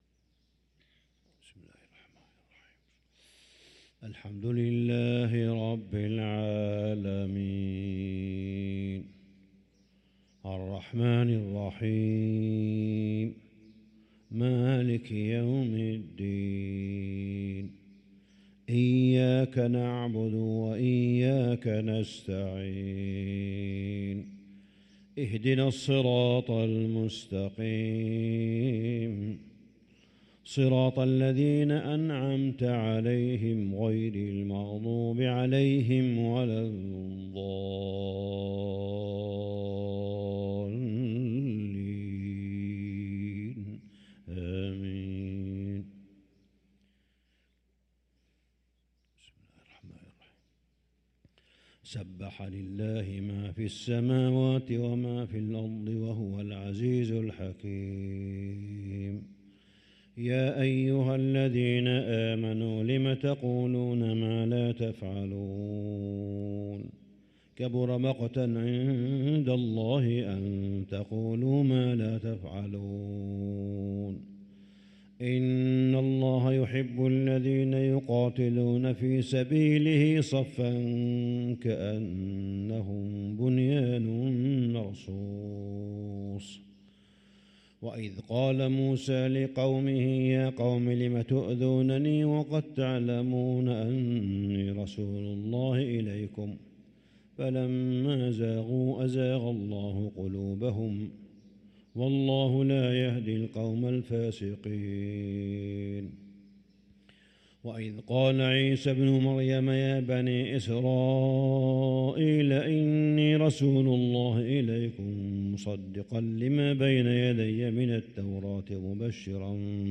صلاة الفجر للقارئ صالح بن حميد 17 ربيع الآخر 1445 هـ
تِلَاوَات الْحَرَمَيْن .